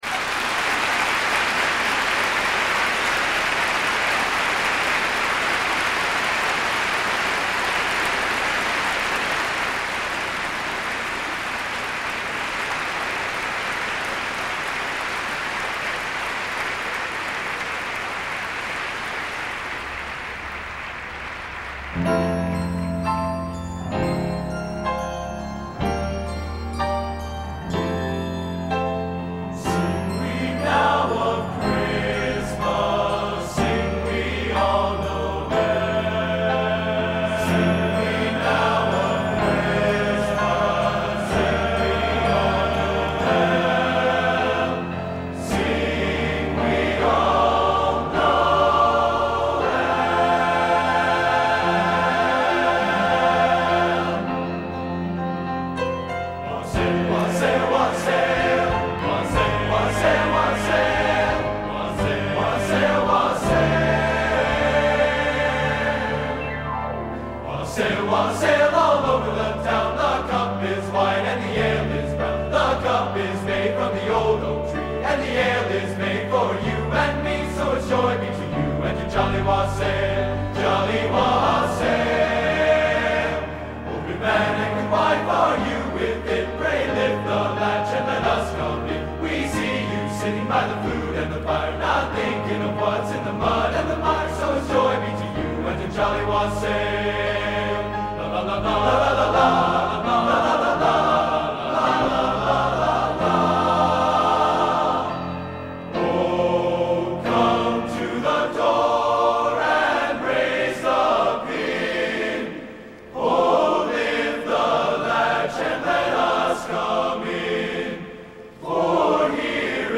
Collection: Christmas Show 1994
Location: West Lafayette, Indiana
Genre: | Type: Christmas Show |